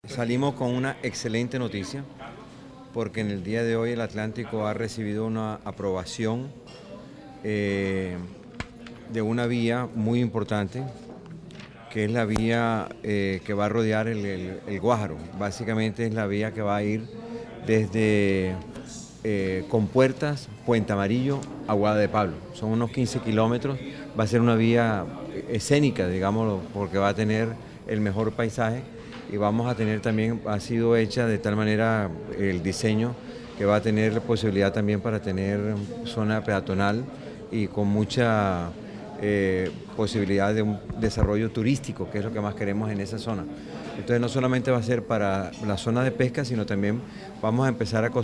Audio-gobernador-Eduardo-Verano-habla-del-OCAD.mp3